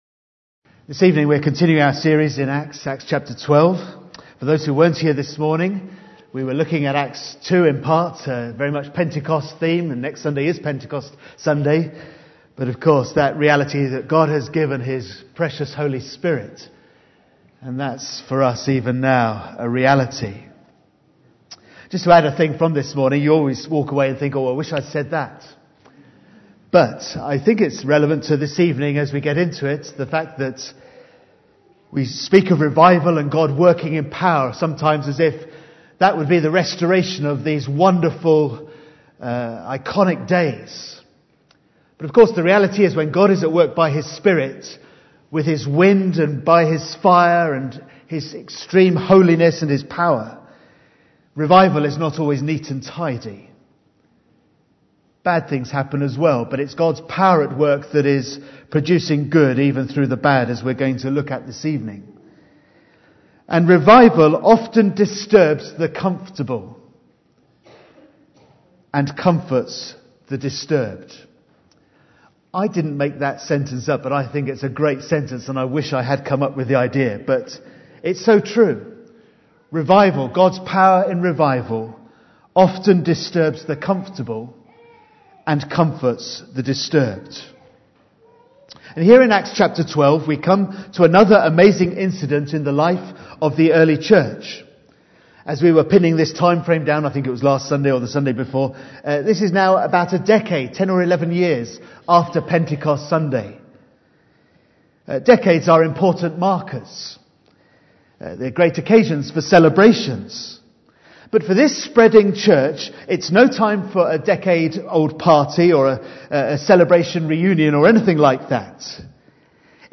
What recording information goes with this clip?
Acts 12:1-25 Service Type: Sunday PM 1.